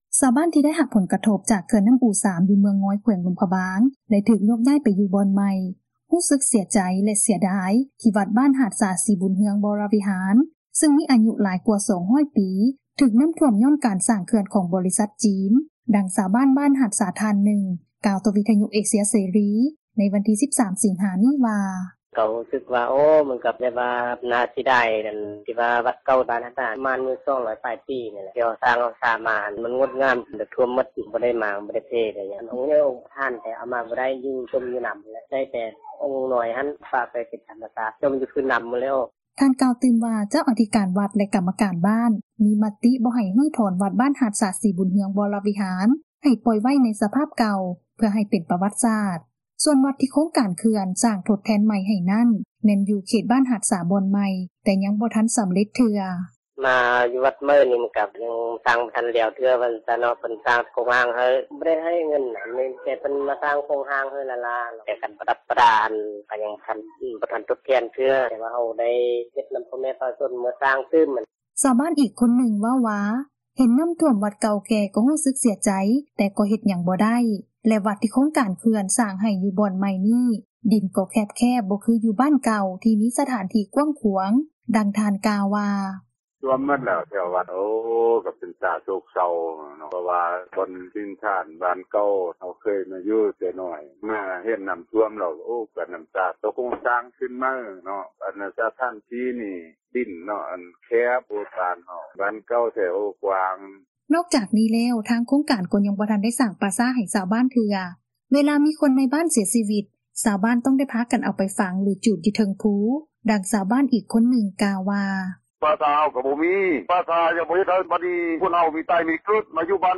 ຊາວບ້ານທີ່ໄດ້ຮັບຜົລກະທົບ ຈາກເຂື່ອນນໍ້າອູ 3 ຢູ່ເມືອງງອຍ ແຂວງຫຼວງພຣະບາງ ແລະຖືກໂຍກຍ້າຍໄປຢູ່ບ່ອນໃໝ່ ຮູ້ສຶກເສັຽໃຈ ແລະ ເສັຍດາຍ ທີ່ວັດບ້ານຫາດສາ ສີບຸນເຮືອງ ວໍຣະວິຫານ ຊຶ່ງມີອາຍຸ ຫຼາຍກວ່າ 200 ປີ ຖືກນໍ້າຖ້ວມ ຍ້ອນການສ້າງເຂື່ອນ ຂອງ ບໍຣິສັດຈີນ, ດັ່ງຊາວບ້ານ ບ້ານຫາດສາ ທ່ານນຶ່ງກ່າວ ຕໍ່ວິທຍຸ ເອເຊັຽເສຣີ ໃນວັນທີ 13 ສິງຫານີ້ວ່າ: